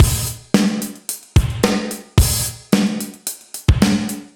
AM_GateDrums_110-02.wav